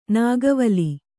♪ nāgavali